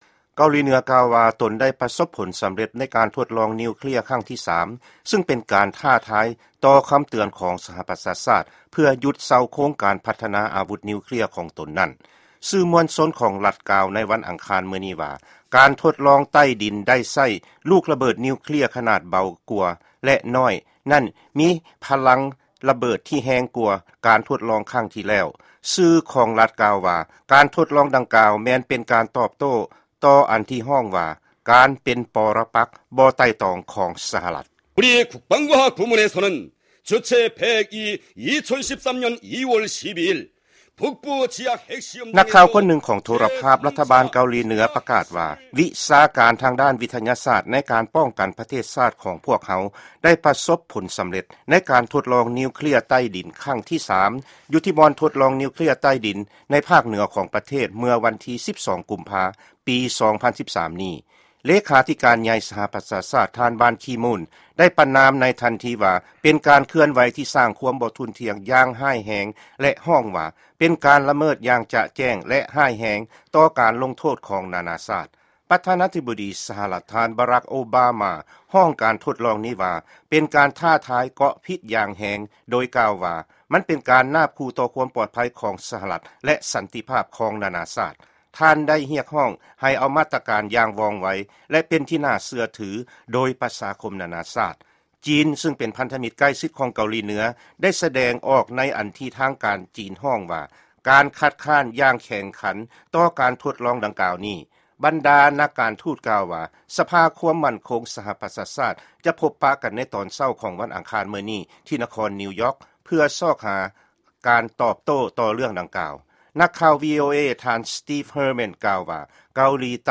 ຟັງຂ່າວ ປະເທດ ເກົາຫລີເໜືອ